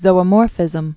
(zōə-môrfĭzəm)